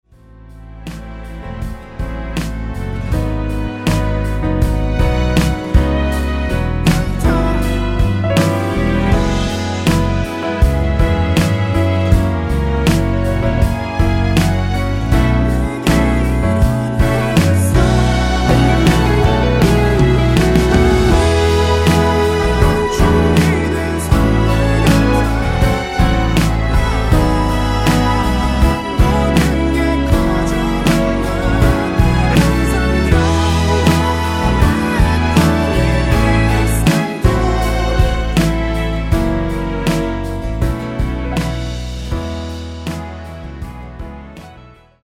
원키 코러스 포함된 MR 입니다.(미리듣기 참조)